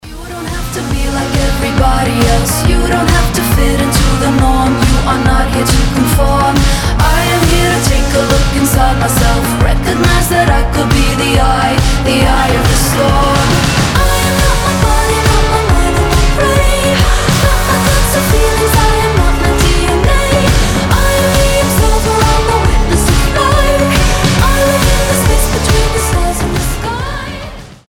• Качество: 320, Stereo
женский голос
indie pop
быстрые